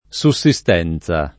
sussistenza [ S u SS i S t $ n Z a ] s. f.